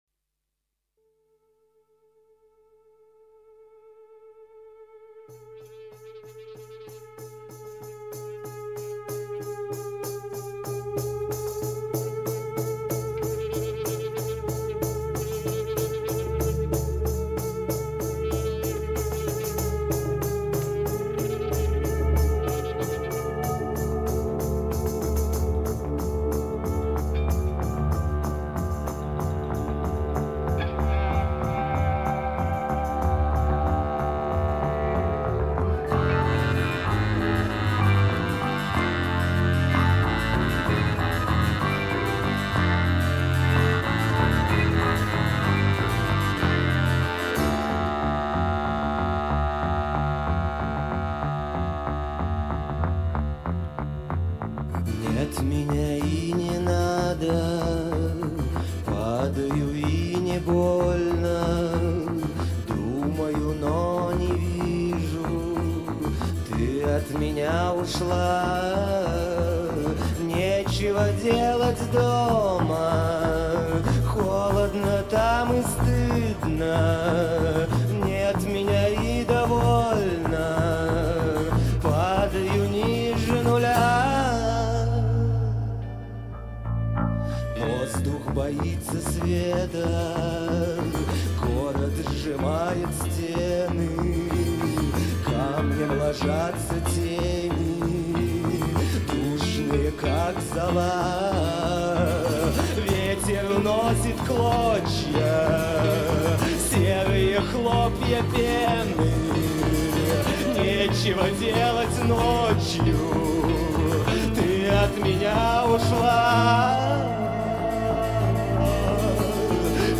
Рок Русский рок